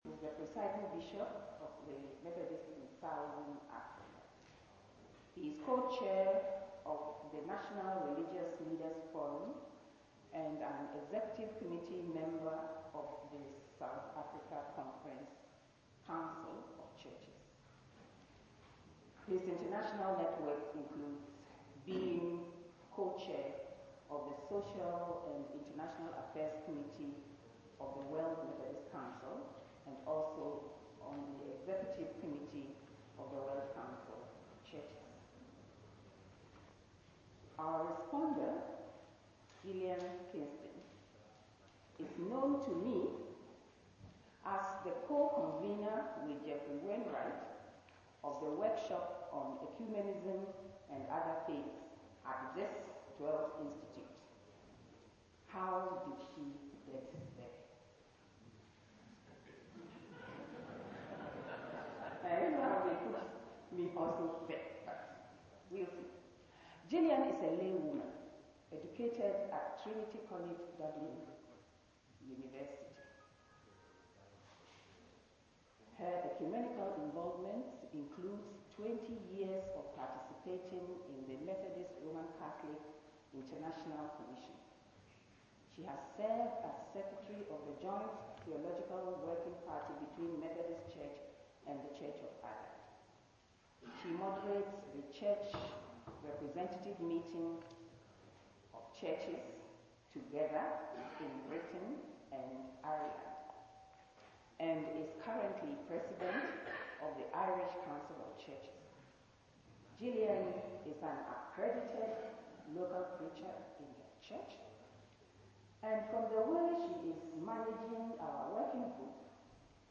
Bishop Ivan Abrahams' plenary paper at the Oxford Institute